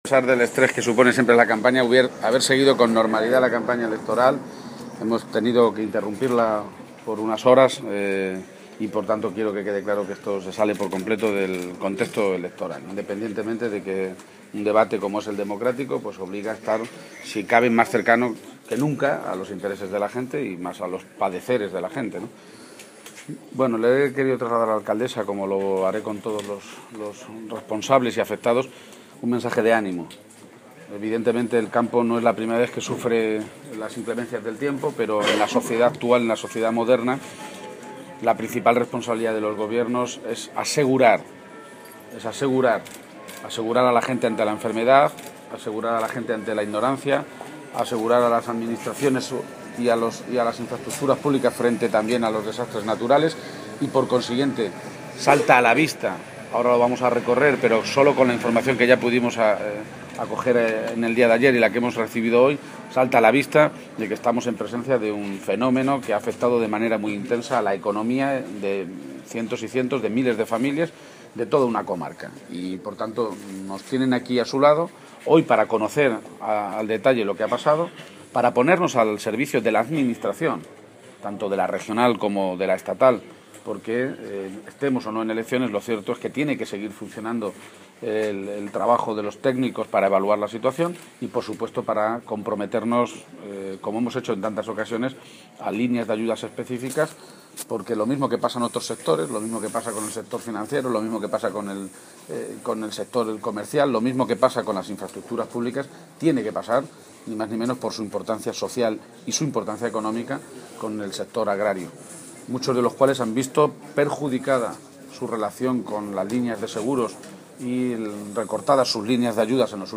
Tras mantener una reunión de una hora con agricultores y cooperativistas, García-Page ha señalado a los medios de comunicación desplazados a la zona que «hoy venimos a acompañar, a apoyar y a recabar información de lo que ha pasado, aunque, después de las primeras informaciones, y a primera vista, lo que ha ocurrido es muy grave».